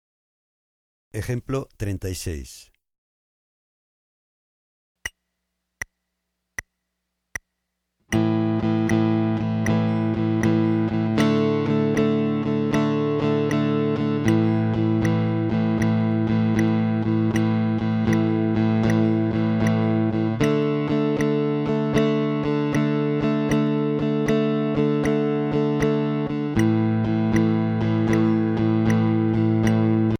Voicing: Guitar/CD